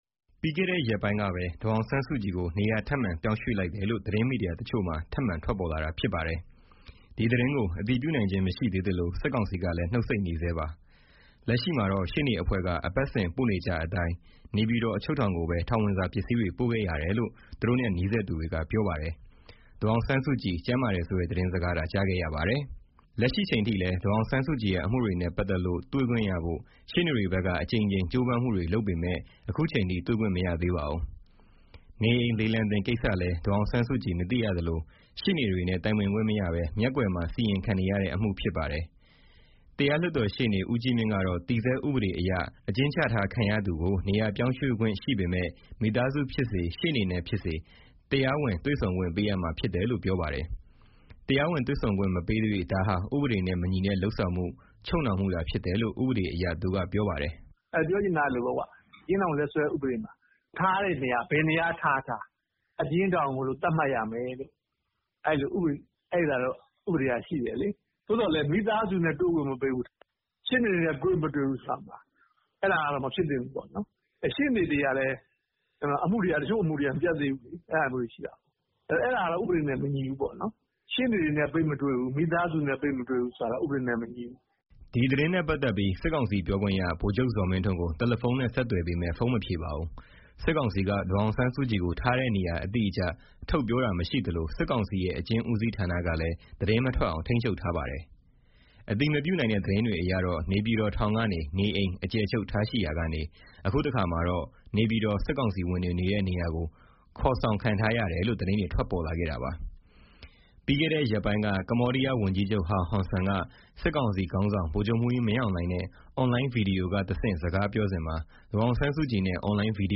ဒါပေမဲ့ ဖိအား အကျပ်အတည်းတွေ များလာရင် ဒေါ်အောင်ဆန်းစုကြည်နဲ့ပတ်သတ်လို့ သတင်းတချို့ ထွက်ပေါ်လာတတ်တာမျိုးနဲ့ အာရုံလွှဲတာမျိုး ရှိတတ်ပြီး အခု ပြည်တွင်းစစ်နဲ့ ပြည်သူတွေ စီးပွါးမပြေလည်တာအပြင် နိုင်ငံတကာ ဖိအားတွေ အောက်မှာ စစ်ကောင်စီက ဒေါ်အောင်ဆန်းစုကြည်ကို အသုံးချနေဆဲရှိတယ်လို့ နိုင်ငံရေးလေ့လာသုံးသပ်သူတဦးက ခုလိုပြောပါတယ်။